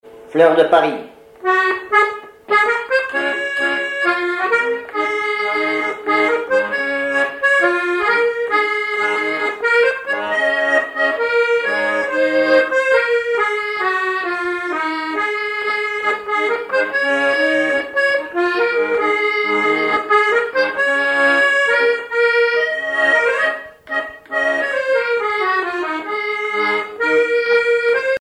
accordéon(s), accordéoniste
Répertoire à l'accordéon chromatique
Pièce musicale inédite